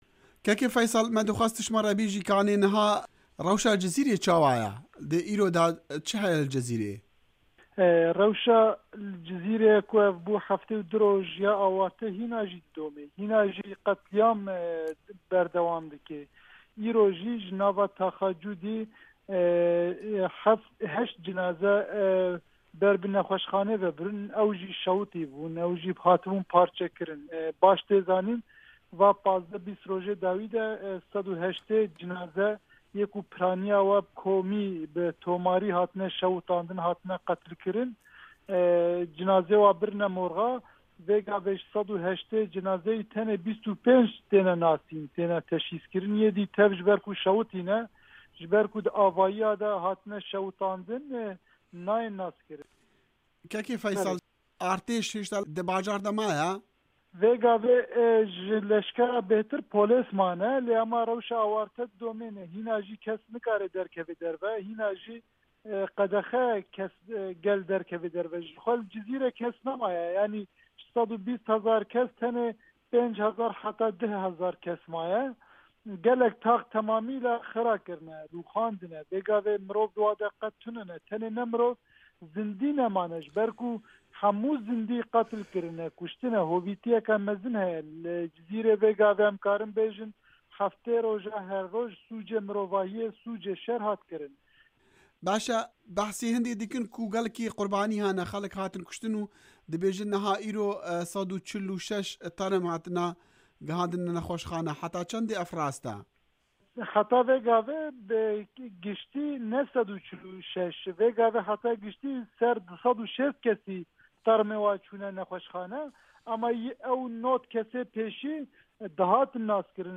Endamê meclisa Tirkiyê ji partiya HDP yê Şirnexê Faysal Sariyildiz jibo Dengê Amerîka hind pêzanînên nû liser qurbanî û rewşa Cizîrê parve kirin.
Temamiya gotinên Faysal Sariyildiz guhdarî bike.